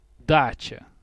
A dacha (Belarusian, Ukrainian and Russian: дача, IPA: [ˈdatɕə]
Ru-dacha.ogg.mp3